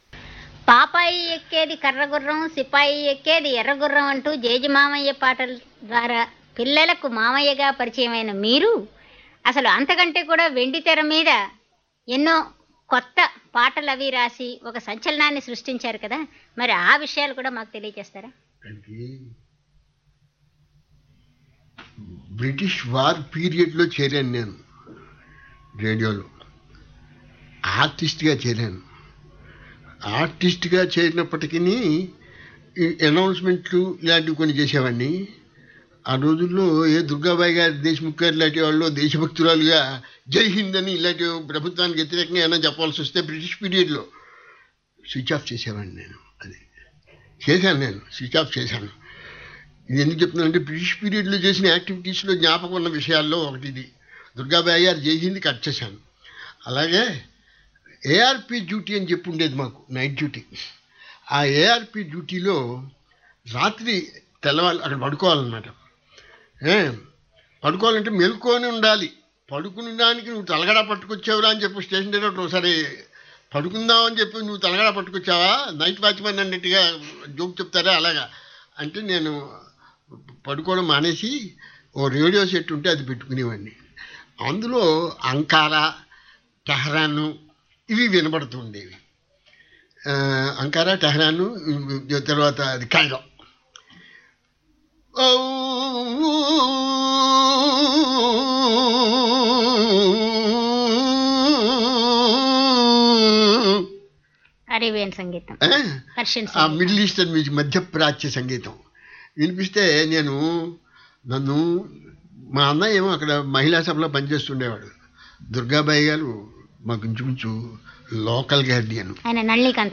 రజనితో రేడియో ఇంటర్‌వ్యూ – ఈమాట
(ఇక్కడ unedited రూపంలో!) రజని సంగీత, సాహిత్య, వ్యక్తిగత వికాసాల గురించిన ఎన్నో వివరాలు వినవచ్చు.
Rajani-interview-B.mp3